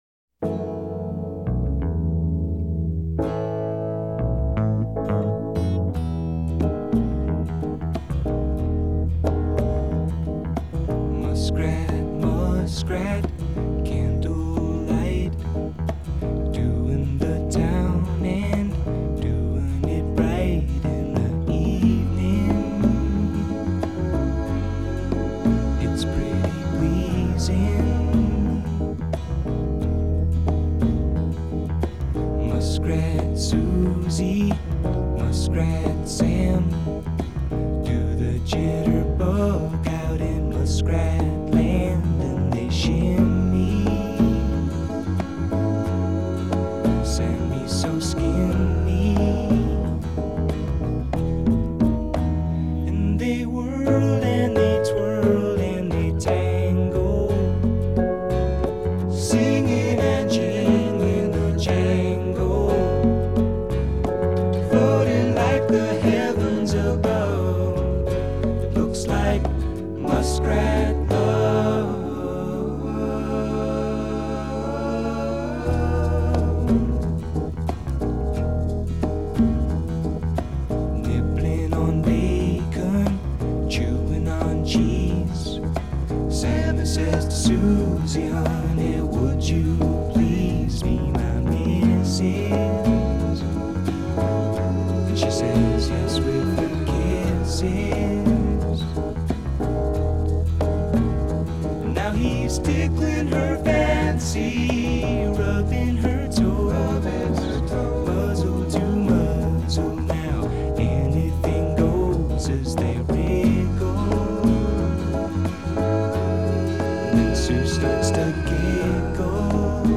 Genre: Folk-Rock.